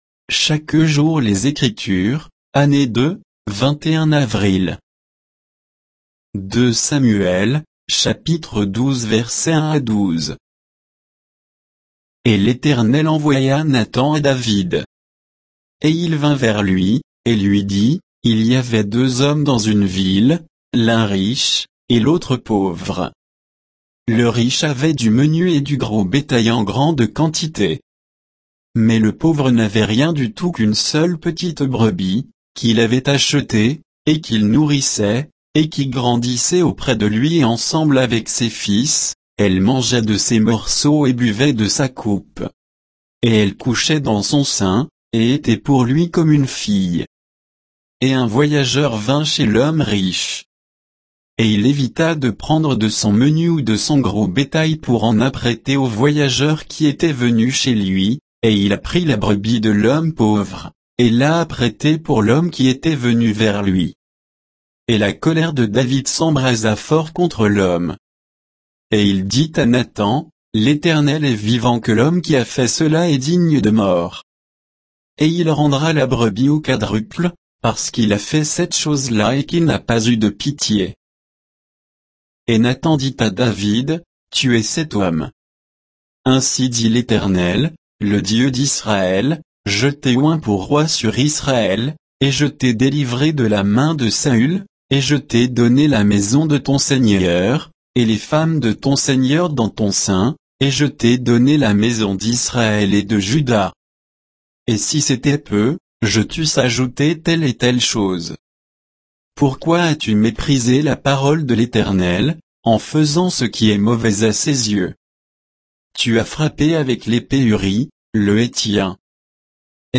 Méditation quoditienne de Chaque jour les Écritures sur 2 Samuel 12